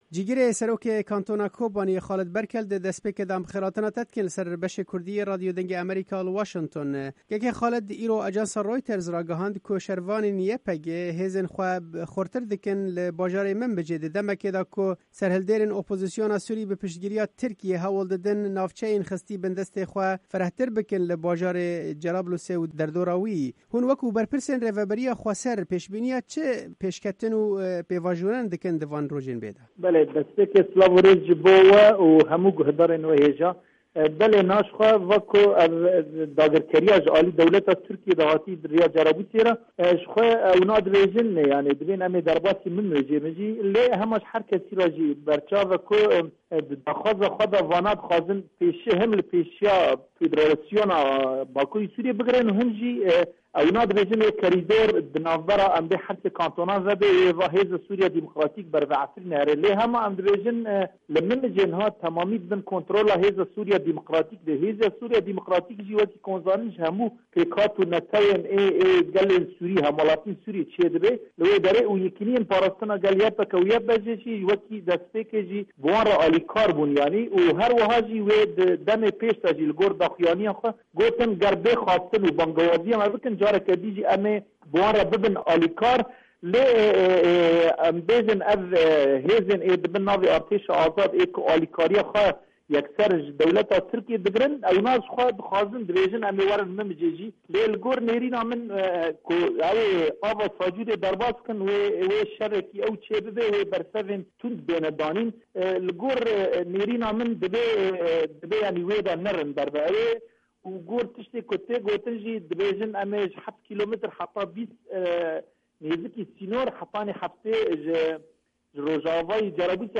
Cîgirê serokê kantona Kobaniyê Xalid Berkel di hevpeyvînekê de li gel Dengê Amerîka rewşa niha ya şer li navçeyê şîrove kir û got: